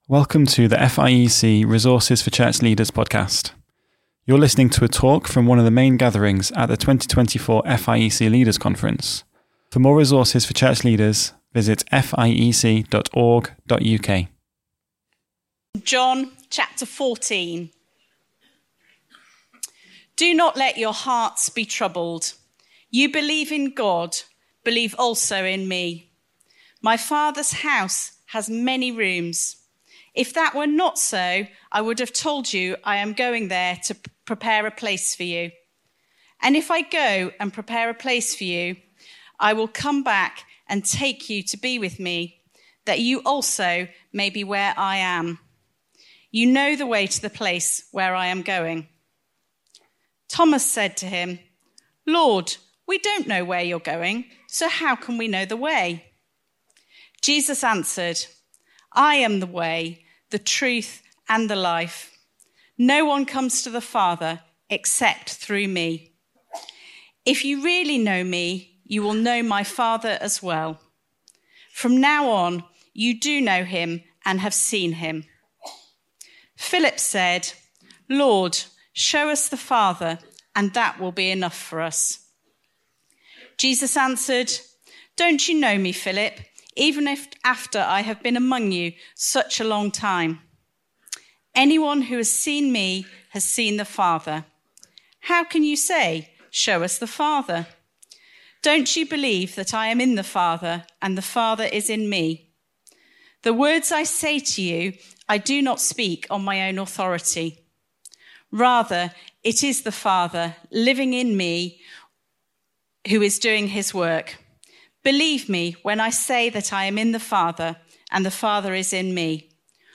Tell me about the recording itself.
I-am-the-Way-the-Truth-and-the-Life-FIEC-Leaders-Conference-2024.mp3